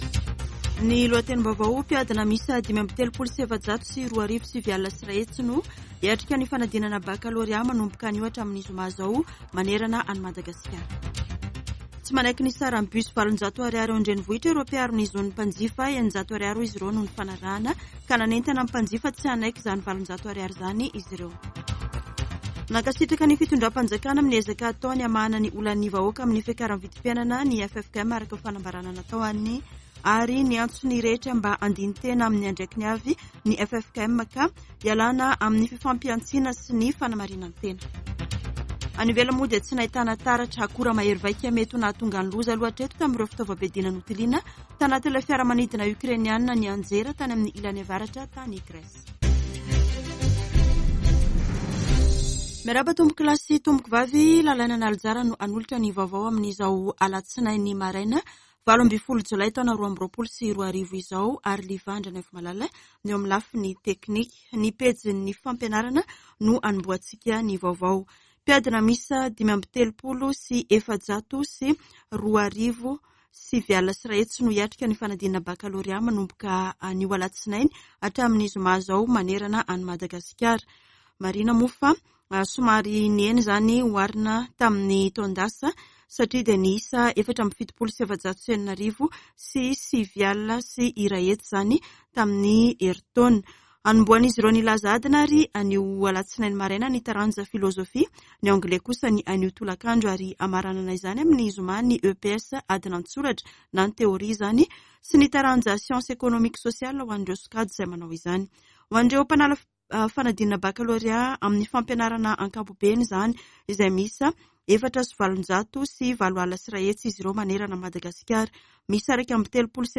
[Vaovao maraina] Alatsinainy 18 jolay 2022